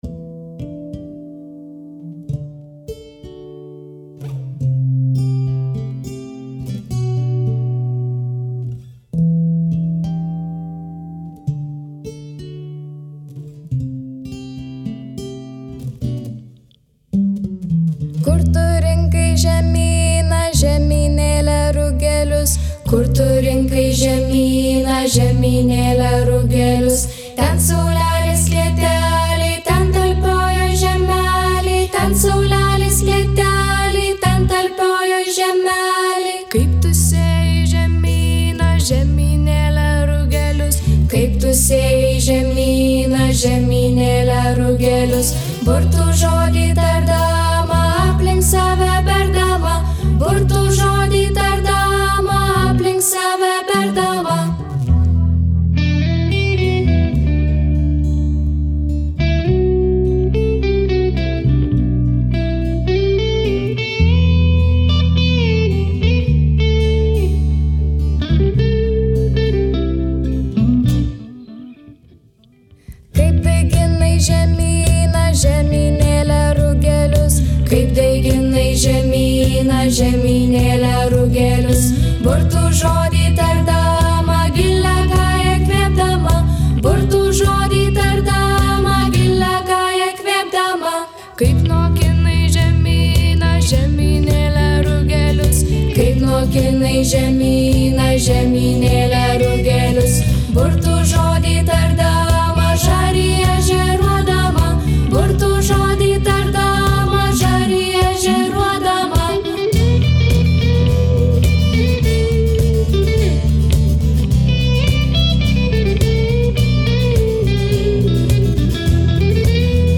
akustinė ir elektrinė gitara, pianinas, perkusija
bosinė gitara
vokalas, barškutis „lietaus šauklys”